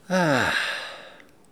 ah-soulagement_02.wav